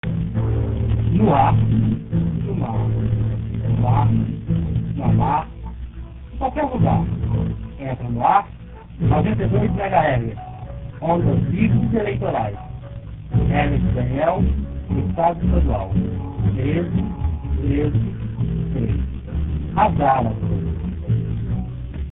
Vinheta da campanha a deputado estadual, gravado por Herbert Daniel.
Opening speech from Herbert Daniel's campaign for state deputy. Rio de Janeiro, 1986, pirate radio.